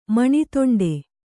♪ maṇi toṇḍe